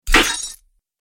دانلود آهنگ دعوا 45 از افکت صوتی انسان و موجودات زنده
دانلود صدای دعوا 45 از ساعد نیوز با لینک مستقیم و کیفیت بالا
جلوه های صوتی